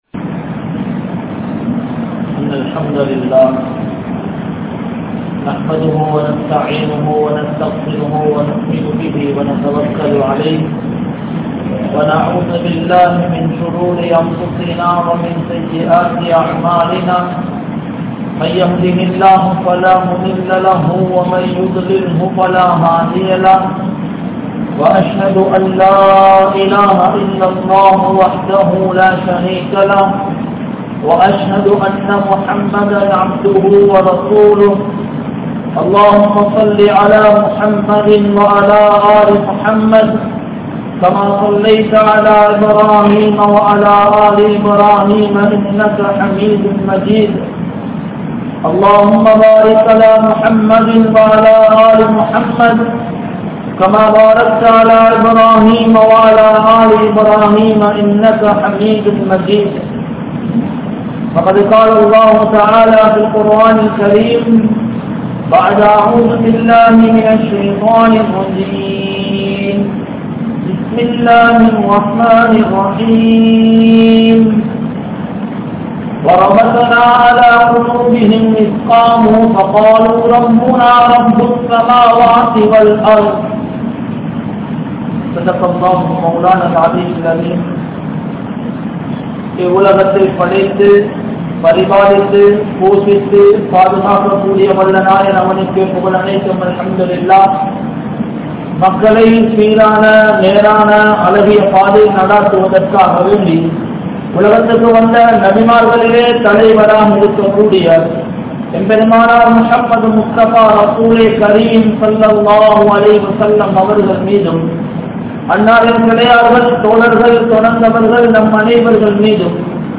Vaalifarhalai Seeralikkum Petroarhal (வாலிபர்களை சீரழிக்கும் பெற்றோர்கள்) | Audio Bayans | All Ceylon Muslim Youth Community | Addalaichenai